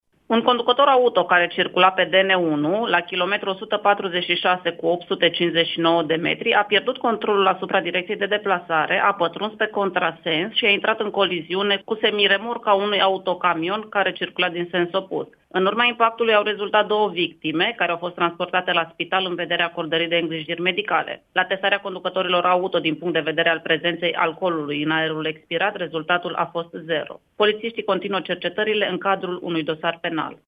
Radio România Mureș